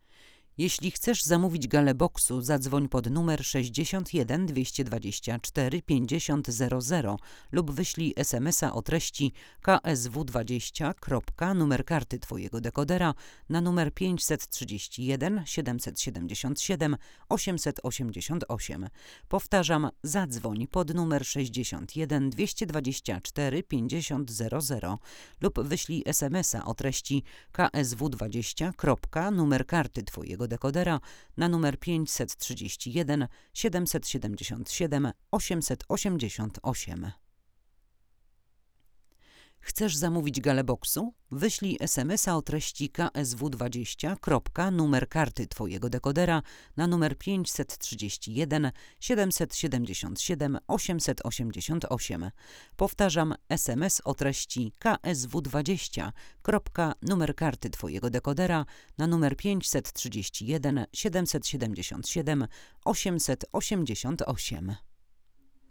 Nagranie wokal żeński
Mocno osadzony, przejrzysty, ale jednocześnie nie wyostrzony dźwięk, którego szuka każdy realizator.